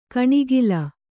♪ kaṇigilakabbu